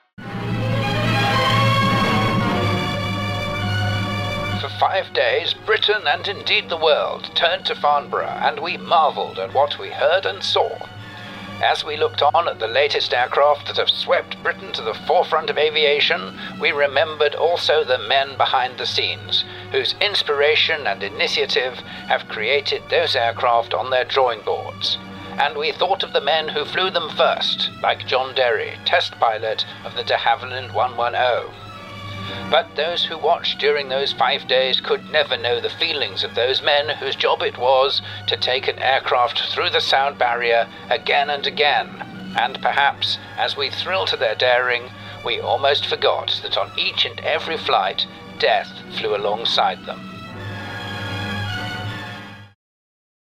Vintage British Voiceover:
Charm, Wit, and Oodles of Character!
The Farnborough Disaster | 1950s Pathe News Style